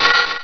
Cri de Lilia dans Pokémon Rubis et Saphir.
Cri_0345_RS.ogg